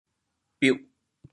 biuh4 long1